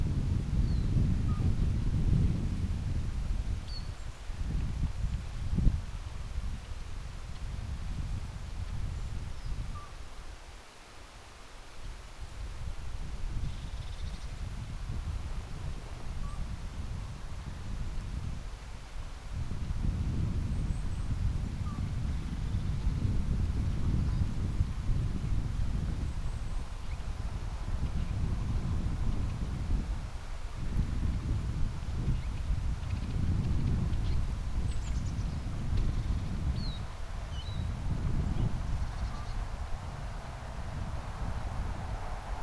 olisiko tuo kyläpöllönen,
kuuluu noin 200 metrin päästä
onko_tuo_kylapollosen_laulua_kuuluu_noin_200_metrin_paasta.mp3